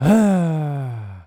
Male_Grunt_Attack_05.wav